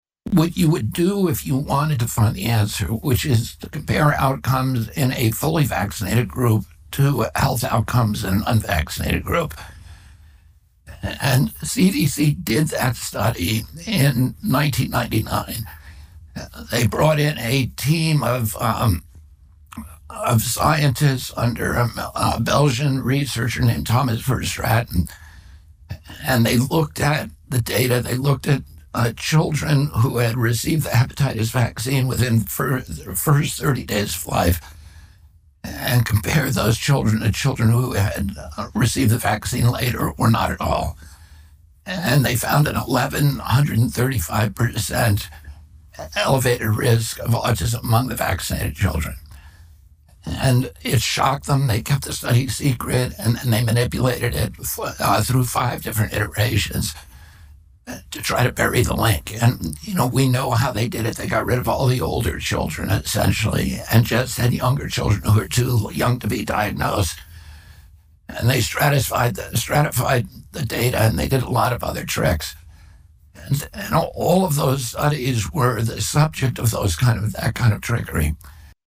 RFK Jr. Interview:
RFK Jr. did an interview with Tucker Carlson earlier this week where the two discussed vaccines.